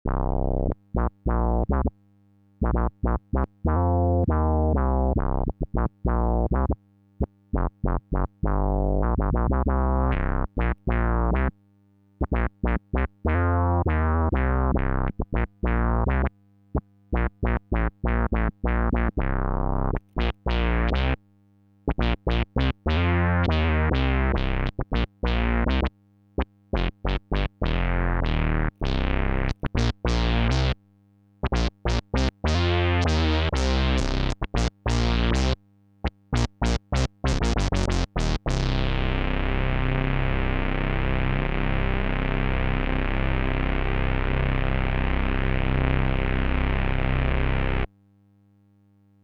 Splitsville (slight PWM) – Future Impact Program Database